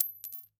household
Coin Dime Dropping on Cement